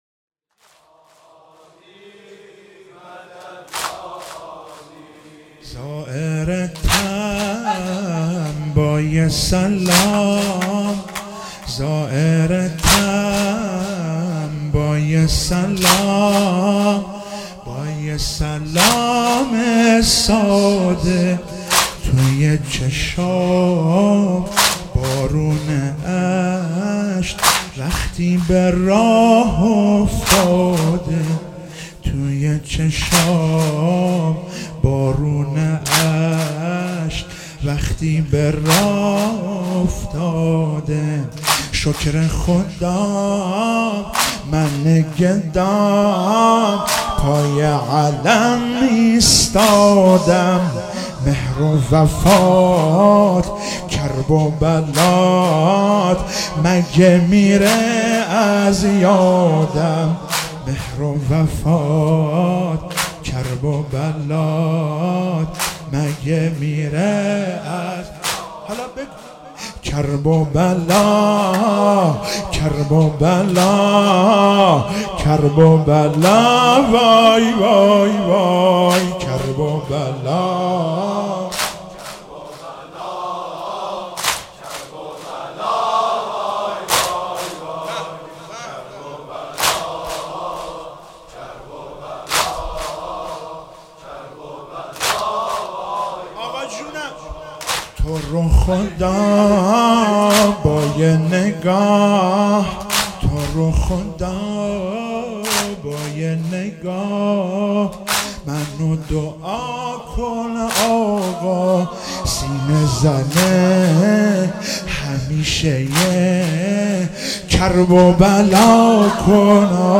شهادت امام هادی (ع)97 - واحد - زائرتم با یک سلام
شهادت امام هادی (ع)